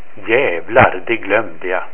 Jävlar, det glömde jag! (= Черт, я совсем забыл!) и Вы услышите его произношение.
Указания по произношению не включены в этот обзор, вместо них Вы можете прослушать звучание (в .wav формате), ”кликнув” на шведский текст.